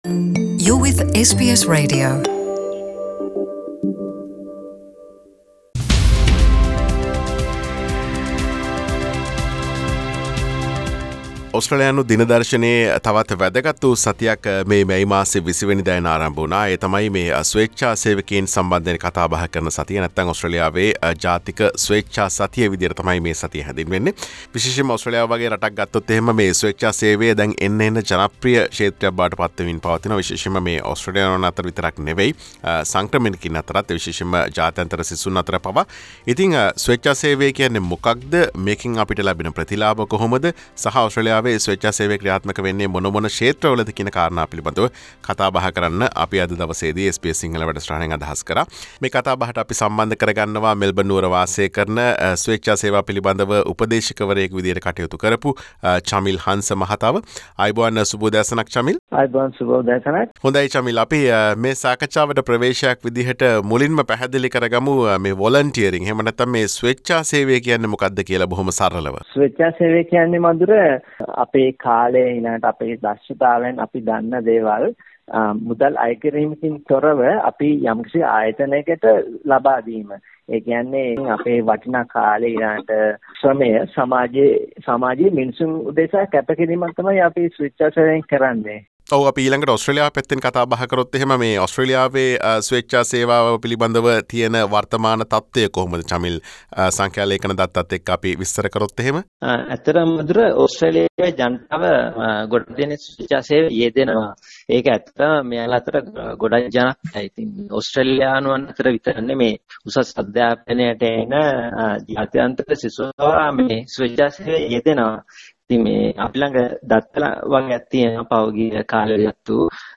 SBS සිංහල වැඩසටහන සිදුකළ සාකච්ඡාව.